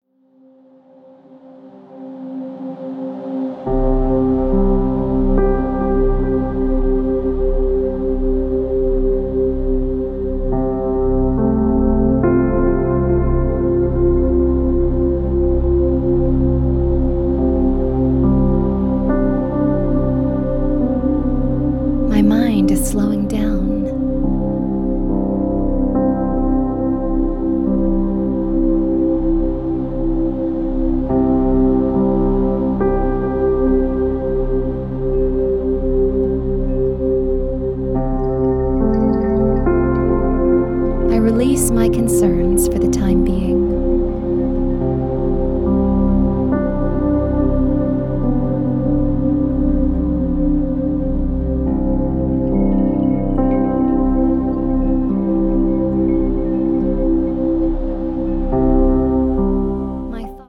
Track two (1 hour)Relaxation Affirmation AUDIBLE VERSION (30 Audible Spoken Affirmations)